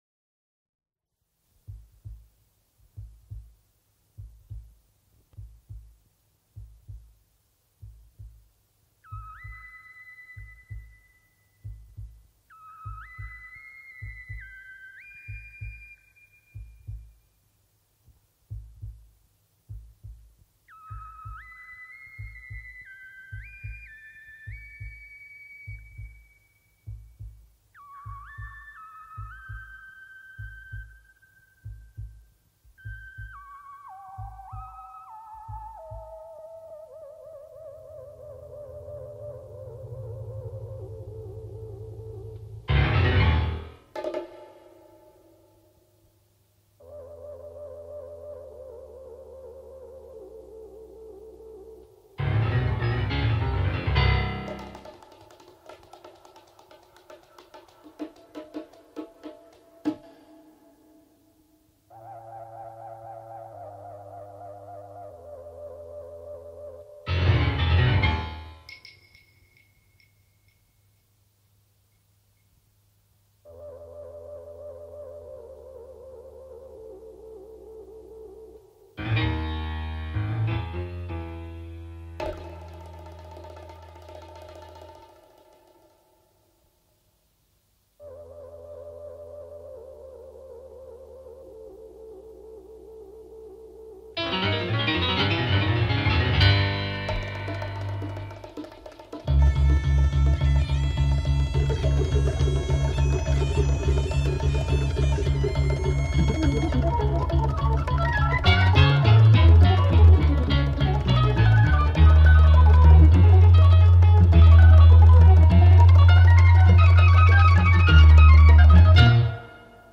با ساختار چندبخشی
Progressive Rock, Art Rock